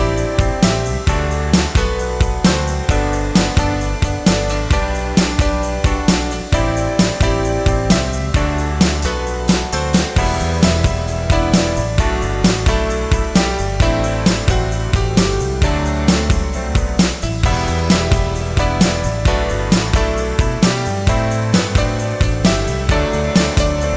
One Semitone Down Pop (2000s) 3:22 Buy £1.50